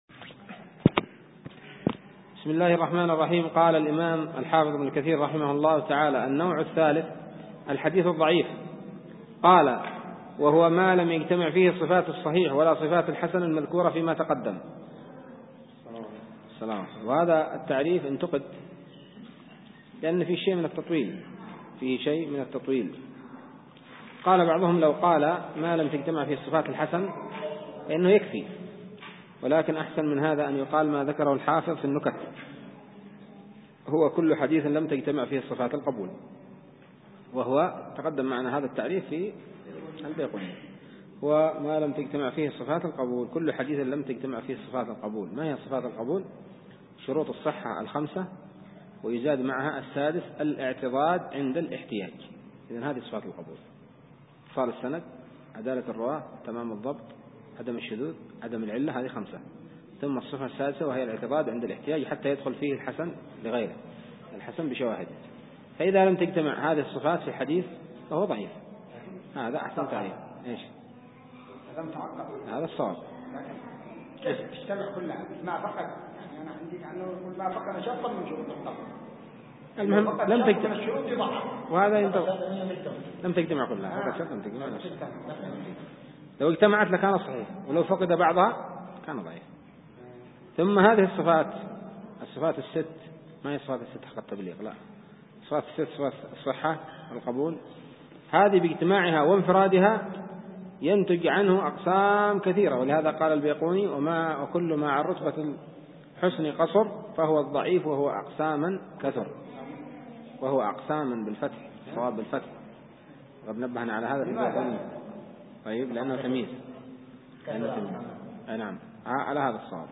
الدرس الثامن من السراج المنير شرح اختصار علوم الحديث لابن كثير